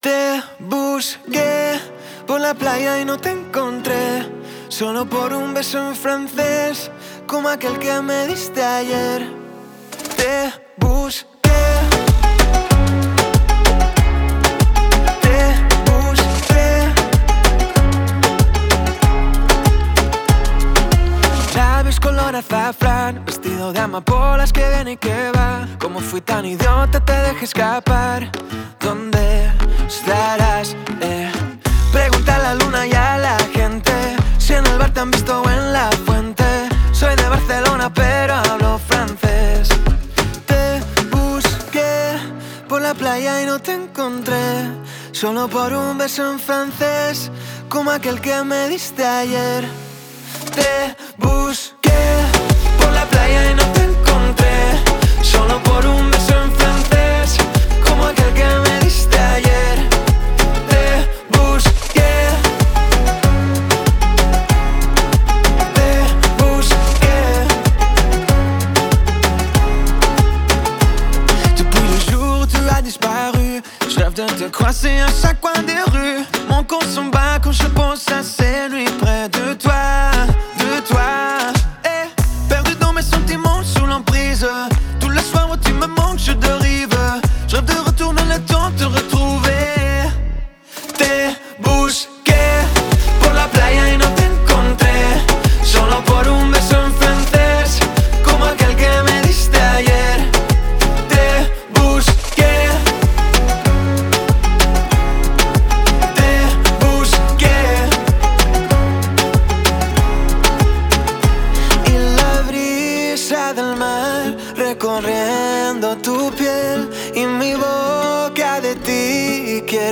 это энергичная и мелодичная песня испанского певца